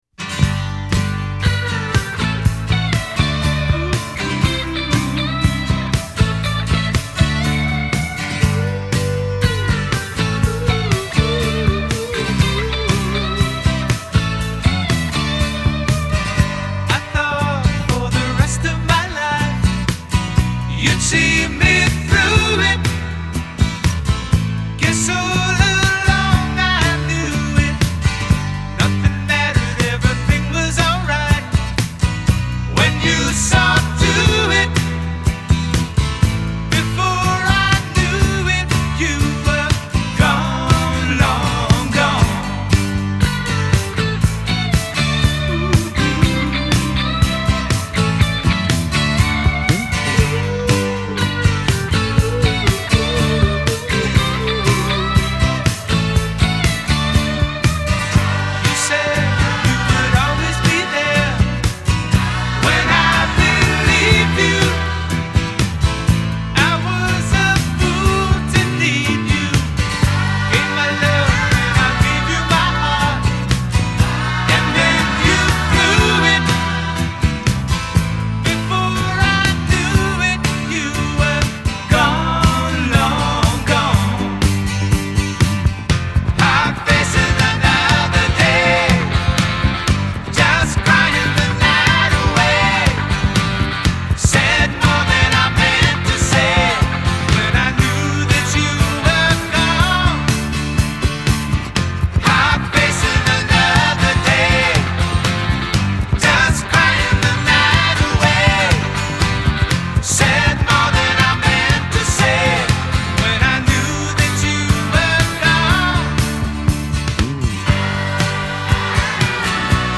Медляки